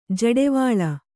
♪ jaḍevāḷa